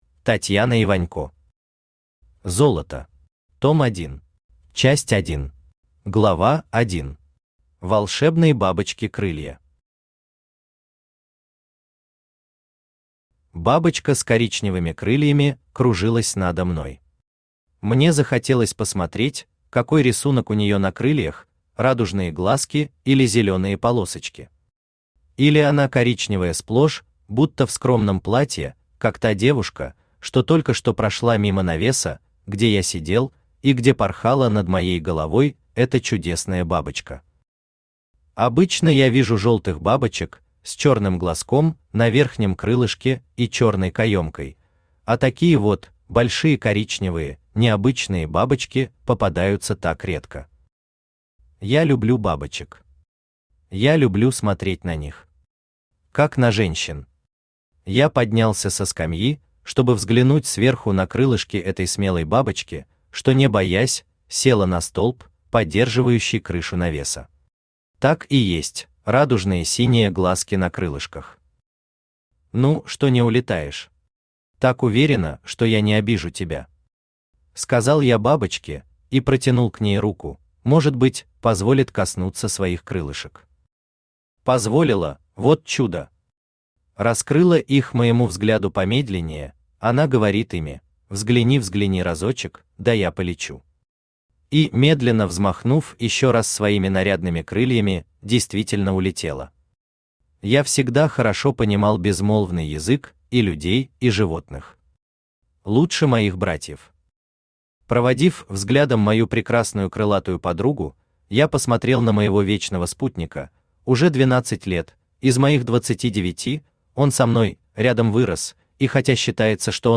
Аудиокнига Золото. Том 1 | Библиотека аудиокниг
Том 1 Автор Татьяна Вячеславовна Иванько Читает аудиокнигу Авточтец ЛитРес.